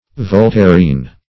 Search Result for " voltairean" : The Collaborative International Dictionary of English v.0.48: Voltairean \Vol*tair"e*an\, a. [Cf. F. voltairien.]